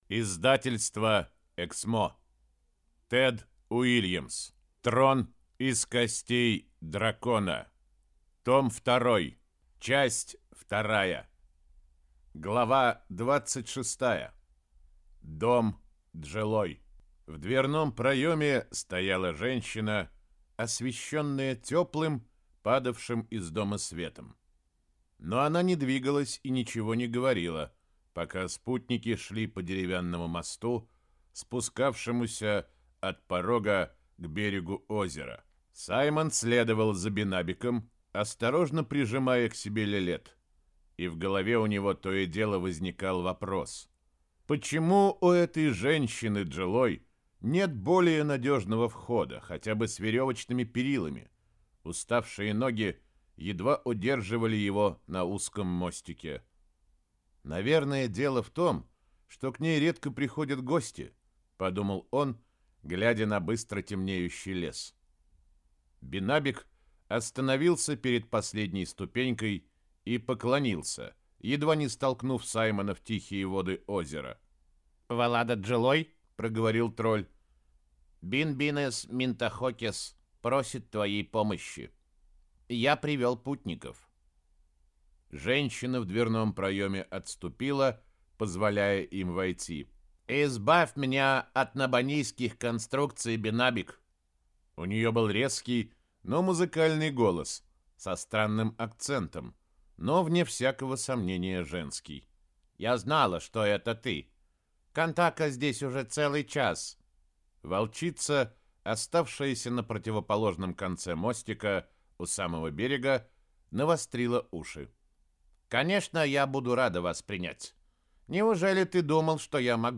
Аудиокнига Трон из костей дракона. Том 2 | Библиотека аудиокниг